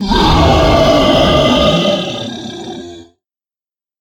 beast_roar_giant.ogg